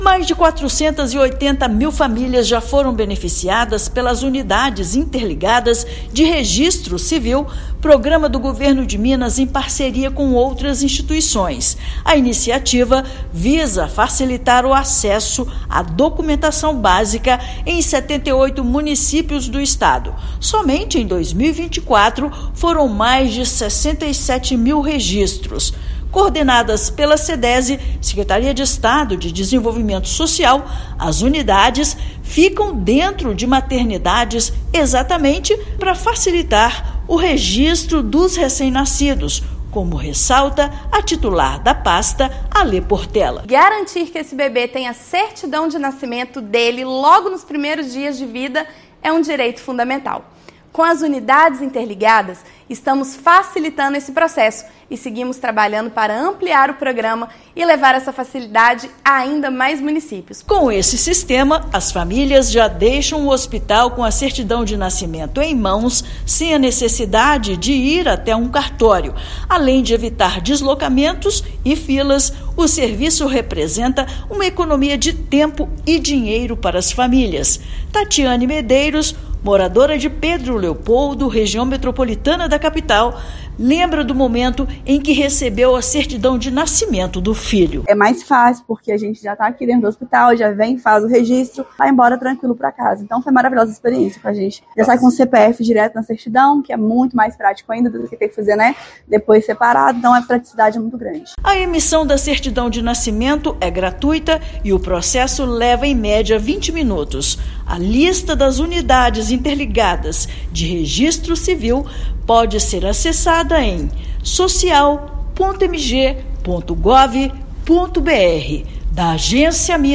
Serviço realizado com parceiros é gratuito e reduz burocracias enfrentadas pelos responsáveis na hora de cuidar do registro dos recém-nascidos. Ouça matéria de rádio.